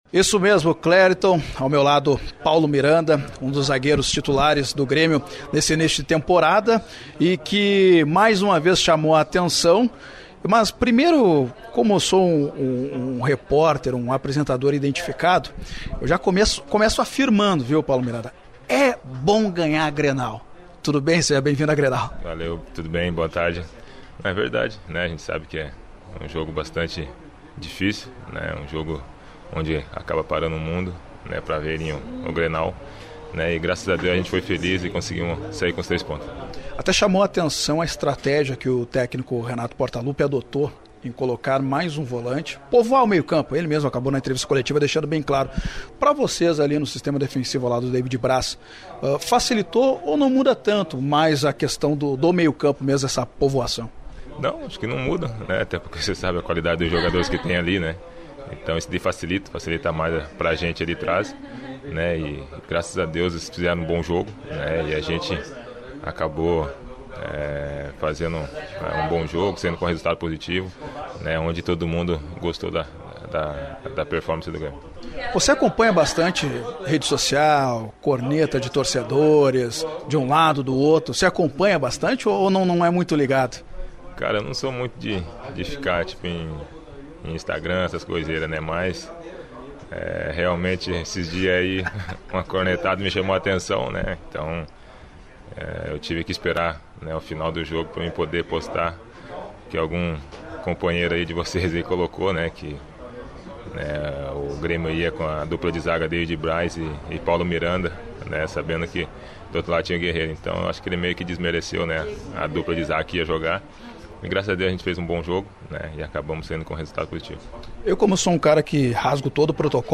Confira trechos da entrevista:
Confira o bate-papo exclusivo da Rádio Grenal com o zagueiro do Grêmio